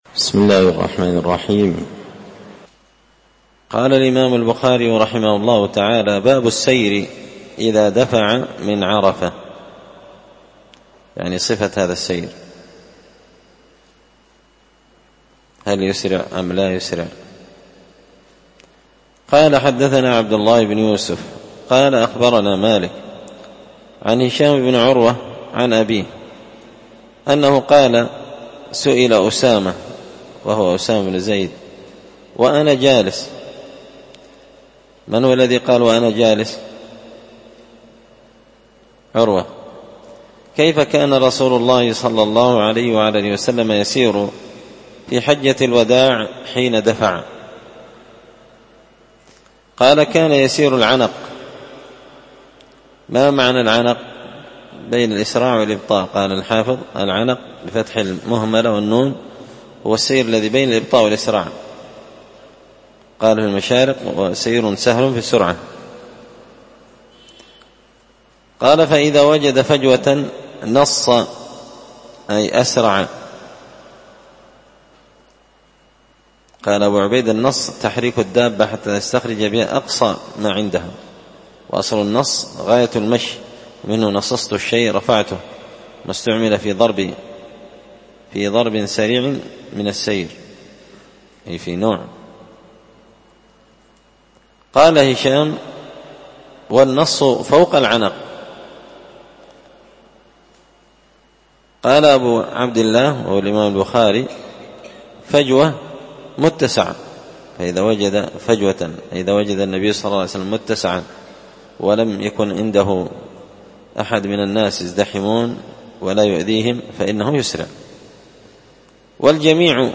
كتاب الحج من شرح صحيح البخاري – الدرس 81